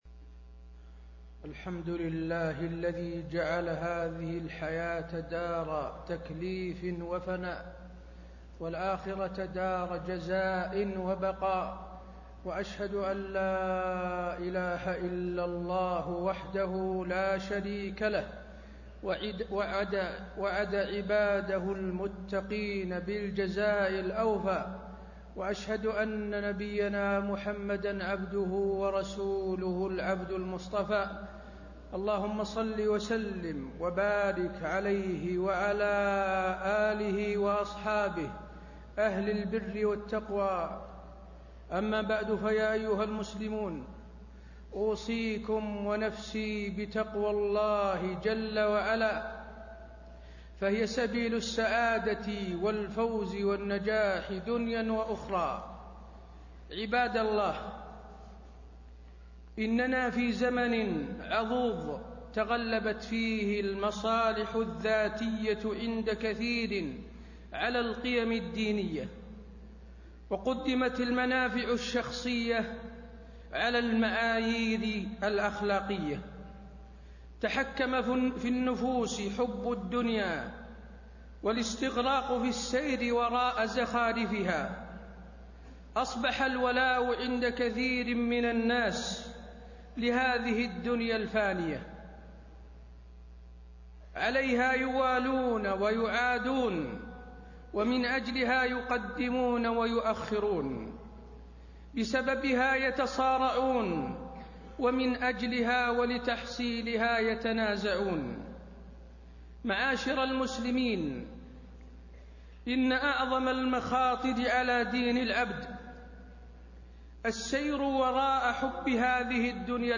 تاريخ النشر ٢ جمادى الآخرة ١٤٣٤ هـ المكان: المسجد النبوي الشيخ: فضيلة الشيخ د. حسين بن عبدالعزيز آل الشيخ فضيلة الشيخ د. حسين بن عبدالعزيز آل الشيخ احذروا الدنيا الفانية